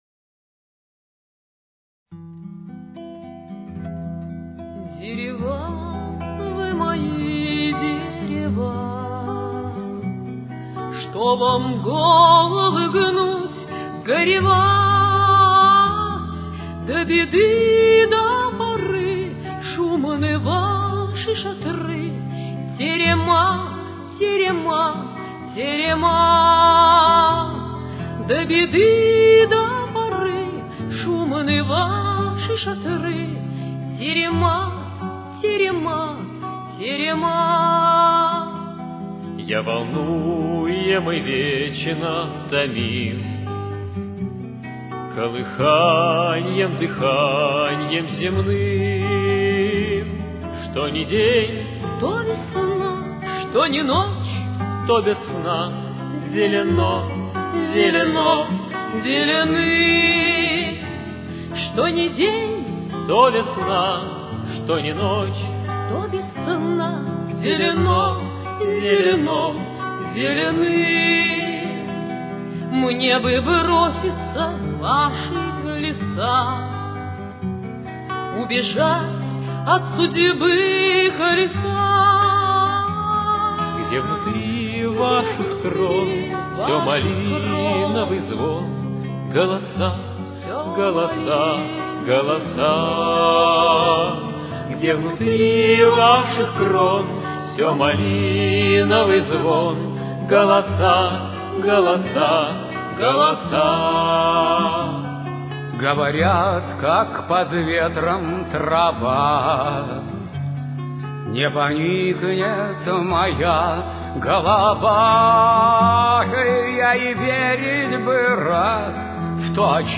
с очень низким качеством (16 – 32 кБит/с)
Темп: 89.